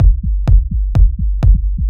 • house - techno beat passage - Am - 126.wav
A loop that can help you boost your production workflow, nicely arranged electronic percussion, ready to utilize and royalty free.
house_-_techno_beat_passage_-_Am_-_126_FXp.wav